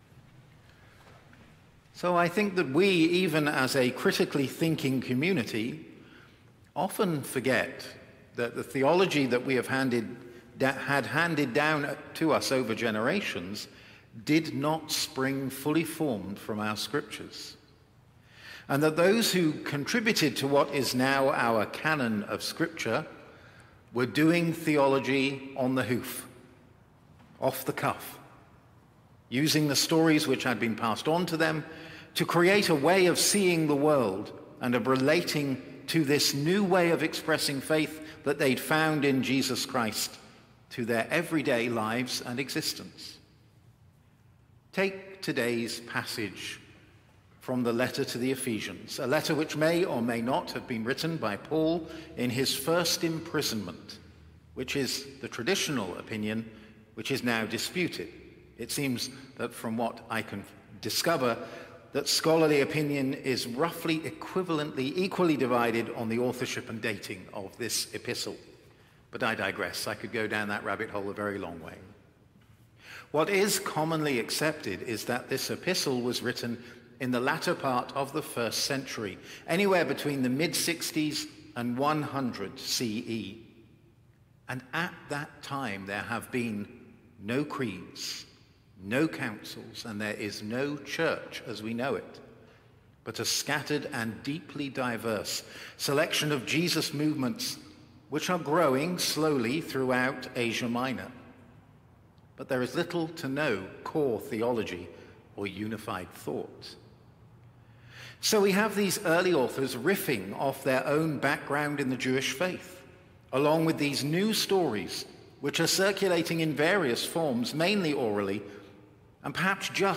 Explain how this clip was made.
Evensong Reflection